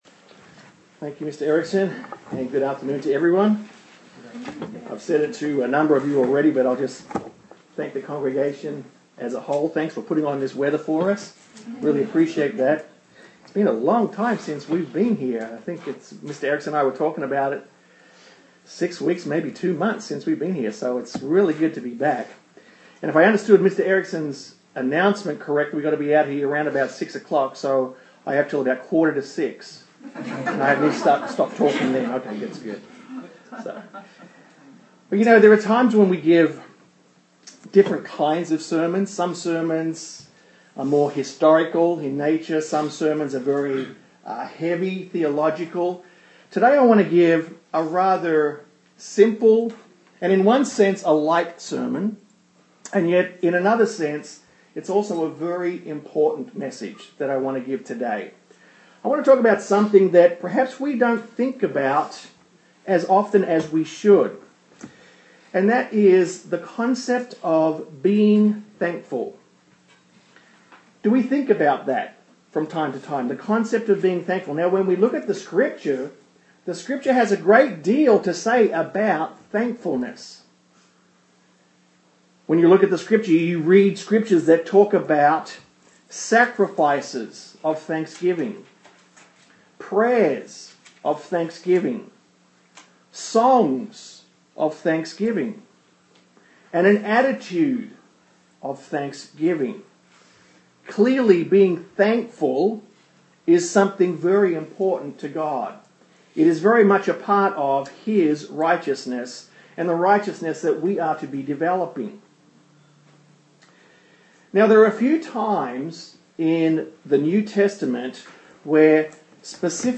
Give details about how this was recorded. Given in Austin, TX